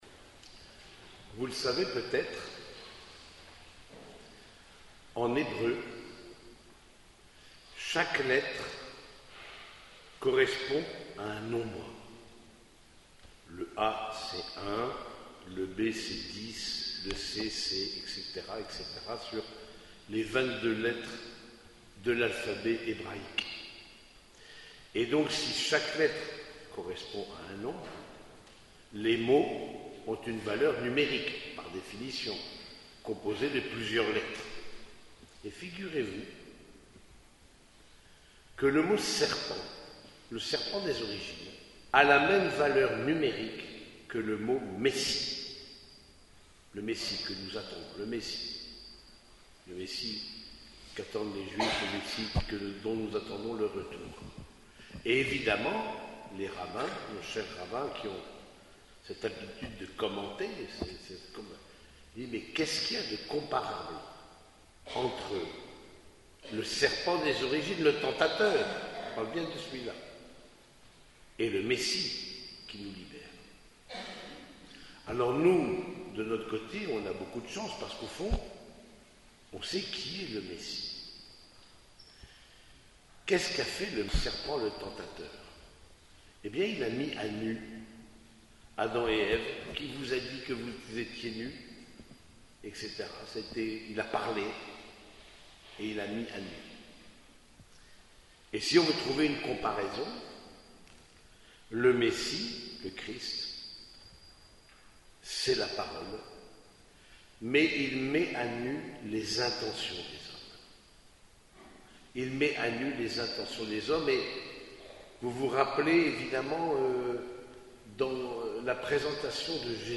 Homélie du 33e dimanche du Temps Ordinaire
Cette homélie a été prononcée au cours de la messe dominicale célébrée à l’église Saint-Germain de Compiègne.